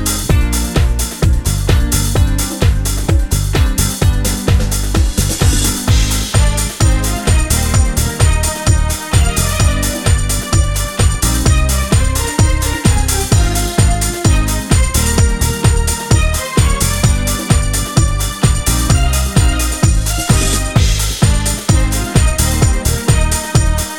Two Semitones Down Dance 3:12 Buy £1.50